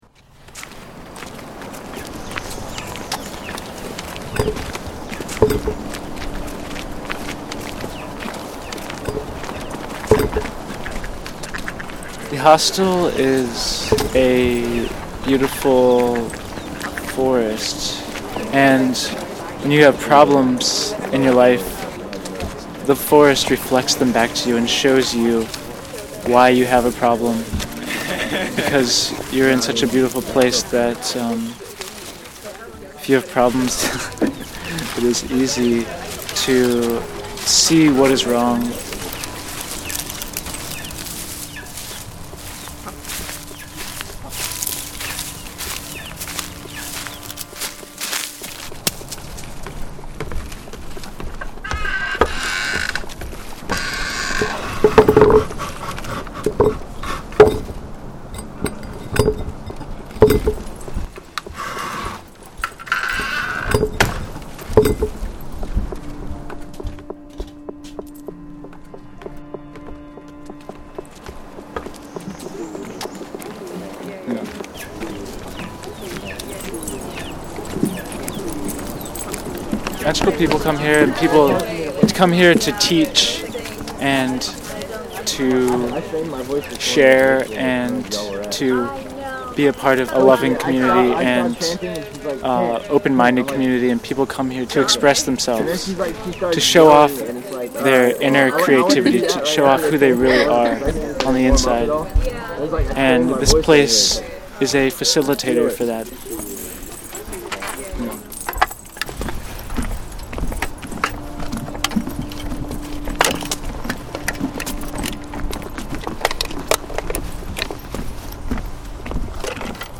In this piece we hear sounds of the place and a staff members trying to put its philosophy into words.